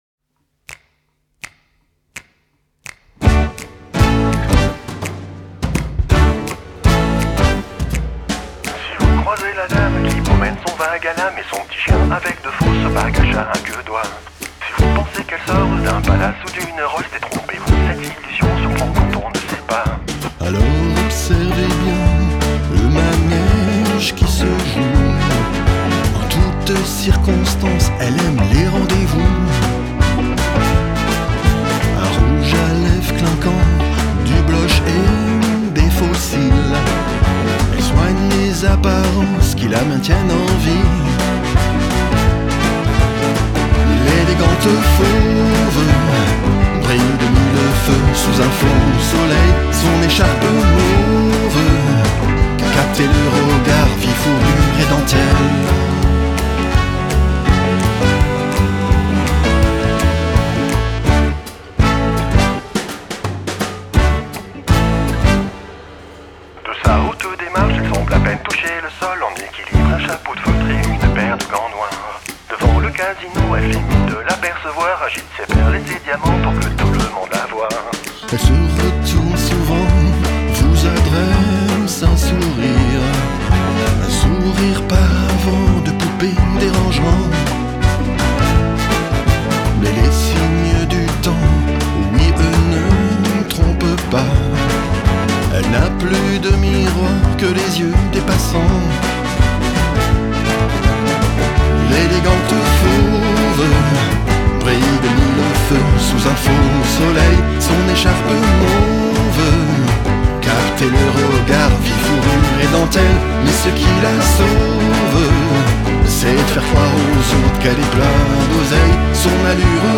Musique NEO-RETRO